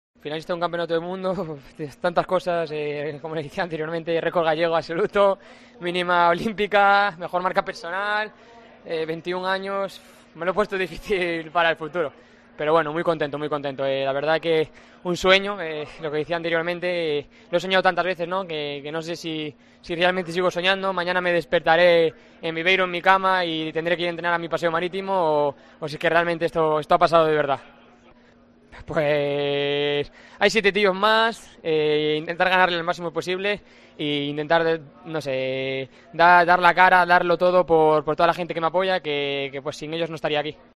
Declaraciones de Adrián ben a la RFEA